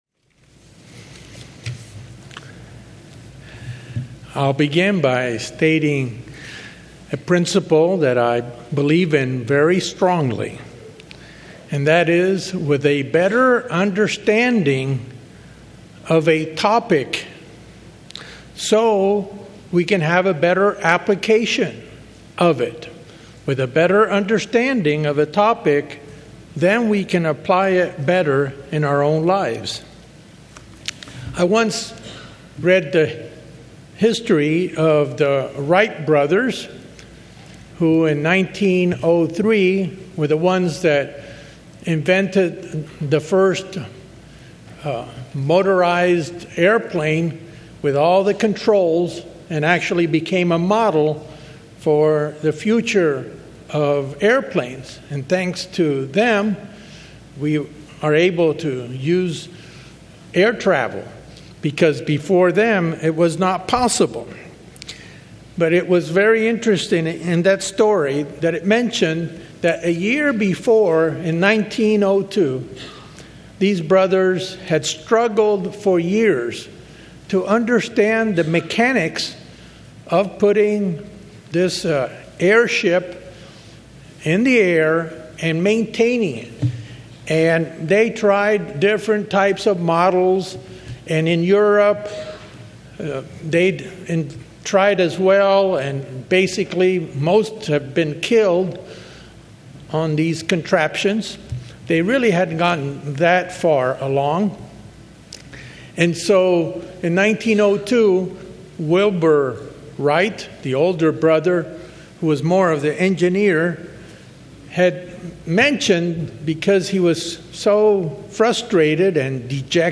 The Pastor reviews scriptures that detail the nature of the God family and the roles of the Father, and Jesus. Through these scriptures we have a clear understanding in Who should be the center of our worship.